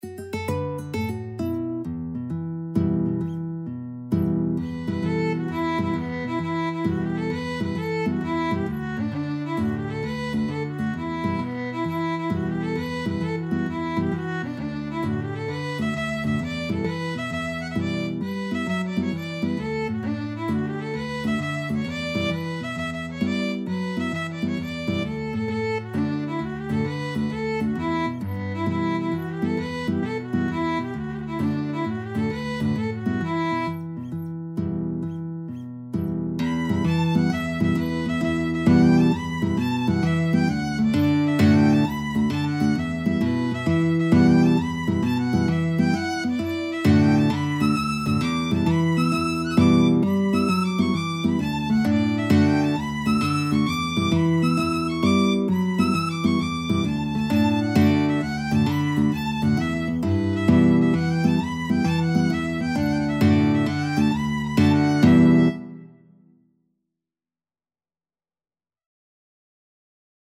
Free Sheet music for Violin-Guitar Duet
9/8 (View more 9/8 Music)
E minor (Sounding Pitch) (View more E minor Music for Violin-Guitar Duet )
Irish